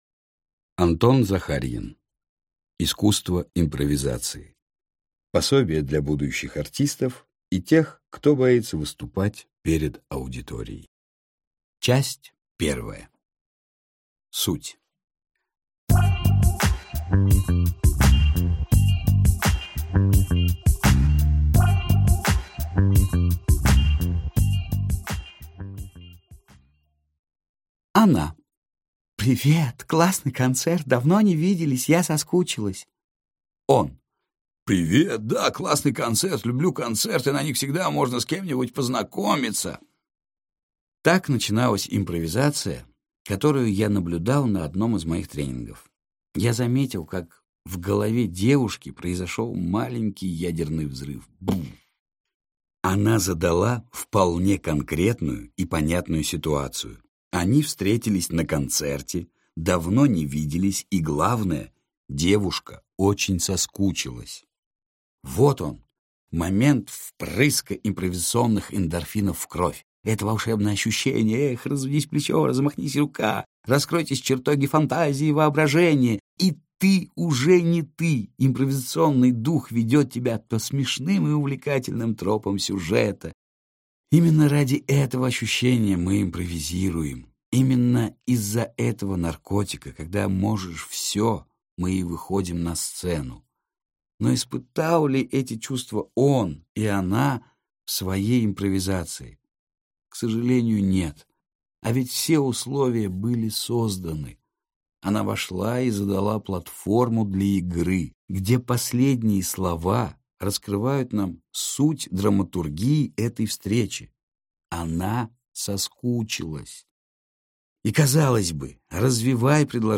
Аудиокнига Искусство импровизации. Пособие для будущих артистов и тех, кто боится выступать перед аудиторией | Библиотека аудиокниг